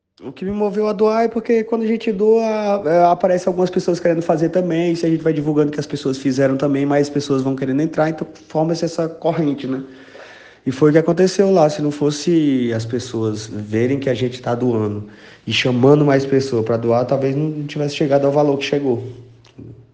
O artista explicou o motivo de suas postagens sobre as doações em entrevista feita pelo WhatsApp: